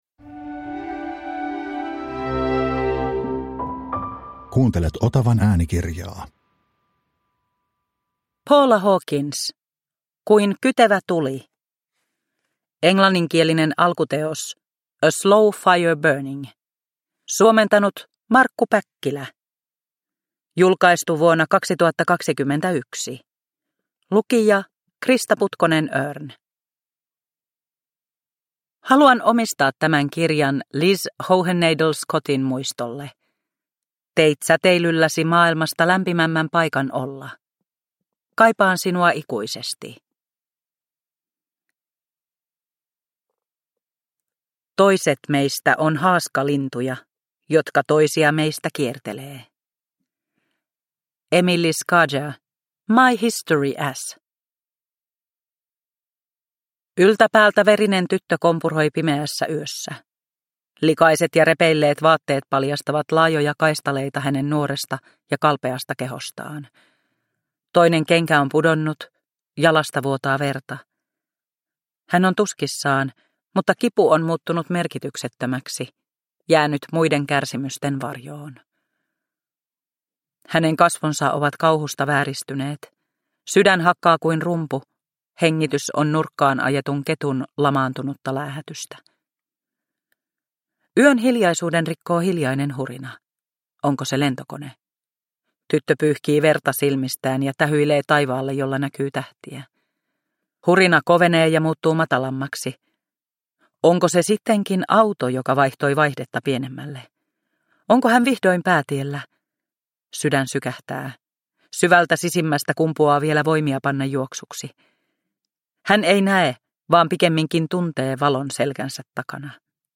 Kuin kytevä tuli – Ljudbok – Laddas ner